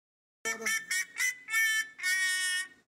goofy ahhh rigntone Meme Sound Effect
This sound is perfect for adding humor, surprise, or dramatic timing to your content.
goofy ahhh rigntone.mp3